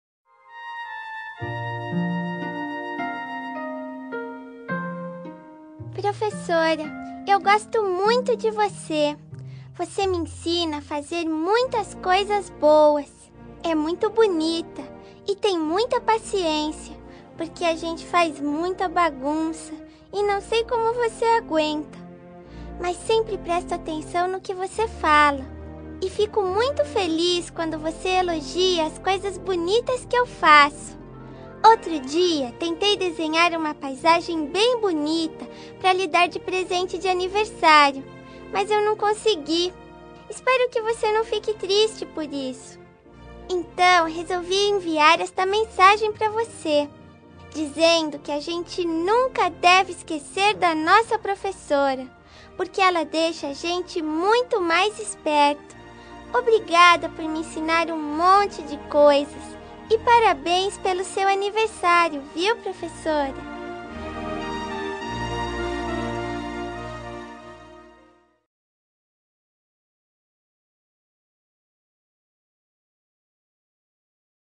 Aniversário Voz Infantil Professora – Voz Feminina – Cód: 256231